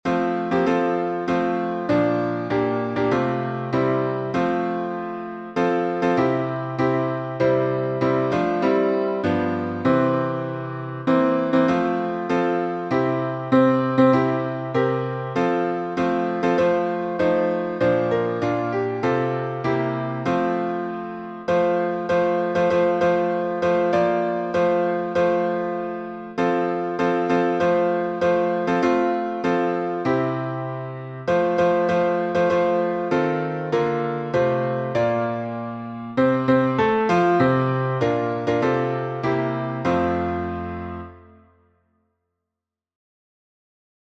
We Have an Anchor — three stanzas.
Key signature: F major (1 flat) Time signature: 4/4